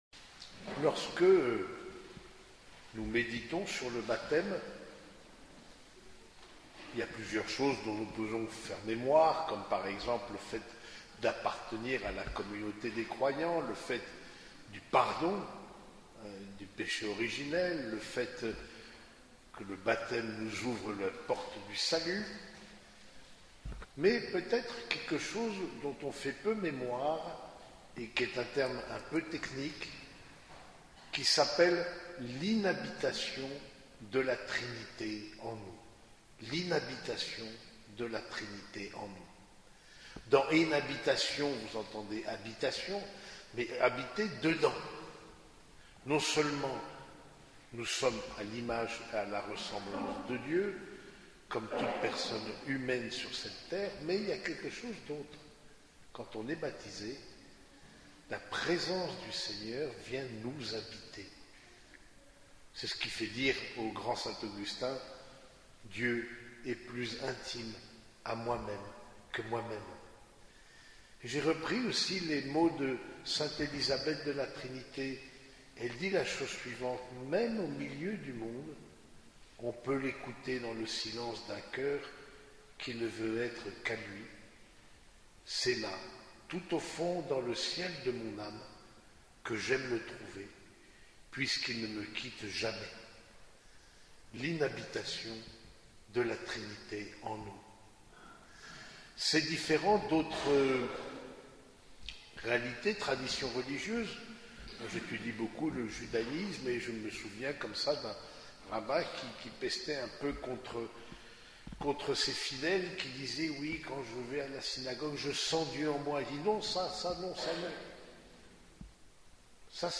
Homélie de la fête du baptême du Seigneur